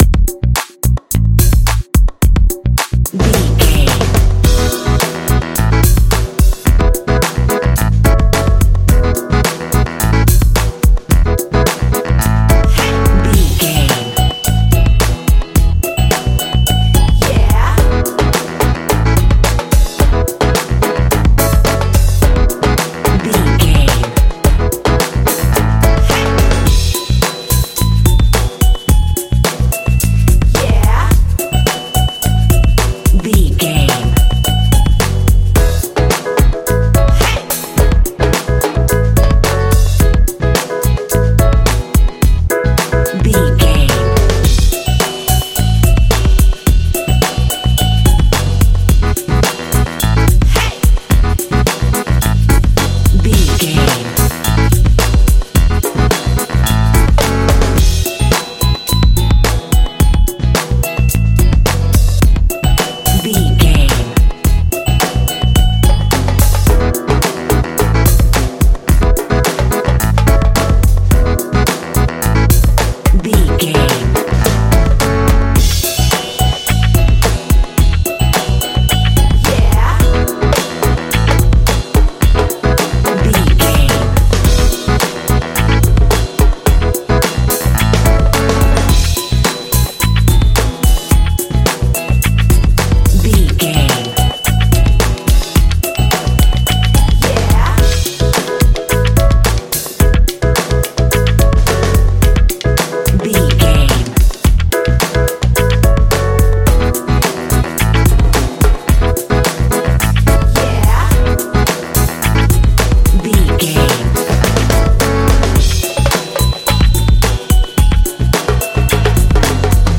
Ionian/Major
groovy
cheerful/happy
bass guitar
drums
percussion
saxophone
electric guitar
piano
contemporary underscore